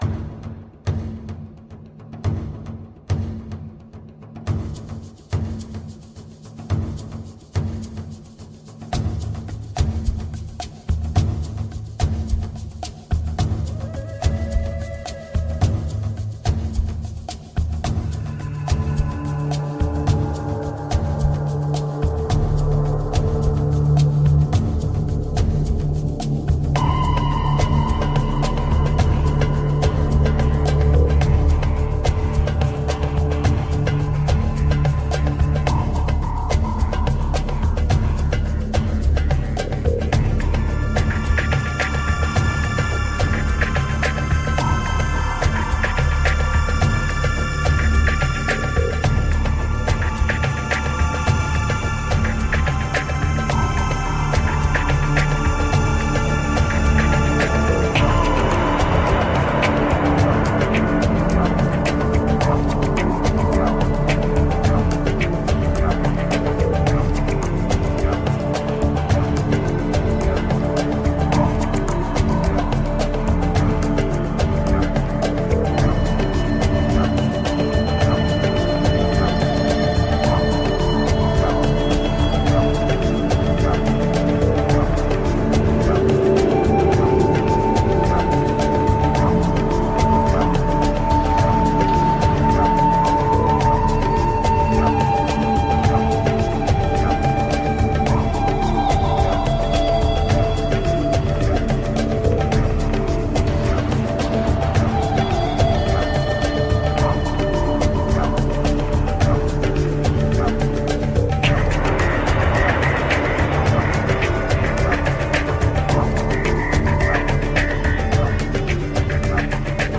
Spiritual new age for today's world.
Tagged as: World, New Age